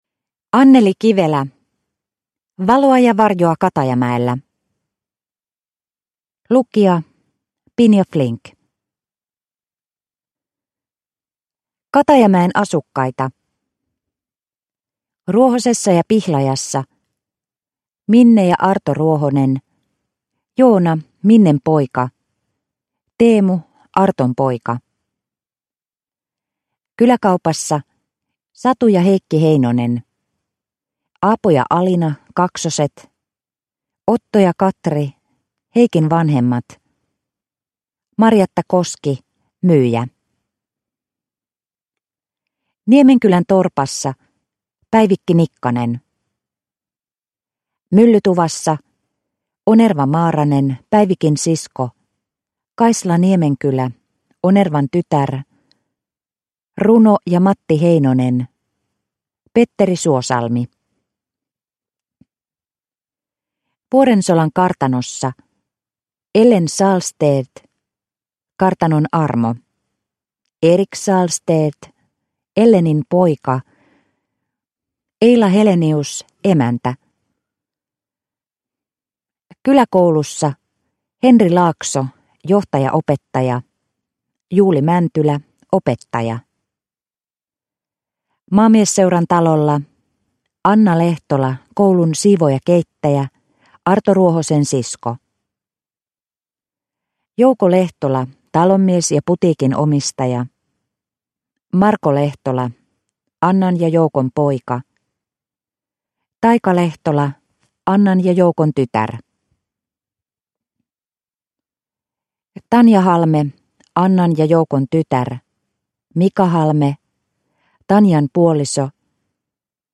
Valoa ja varjoa Katajamäellä – Ljudbok – Laddas ner